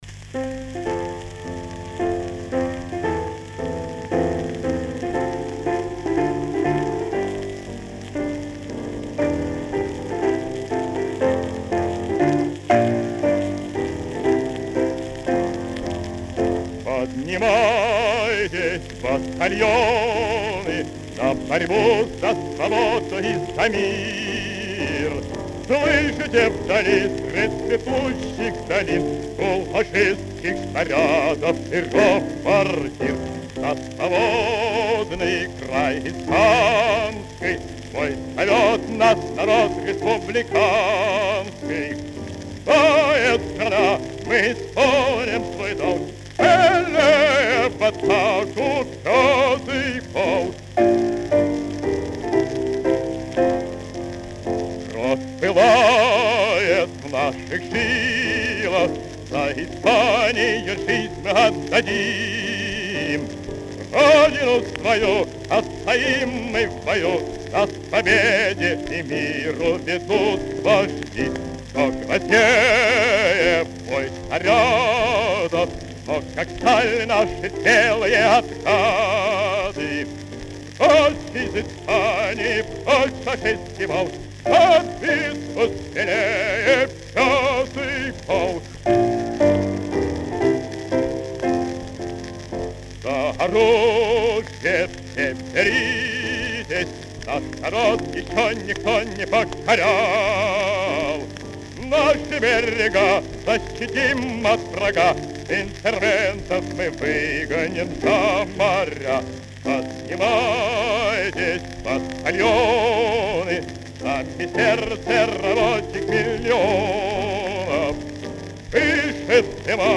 Боевой марш времён гражданской войны в Испании.
ф-но) Исполнение 1938г.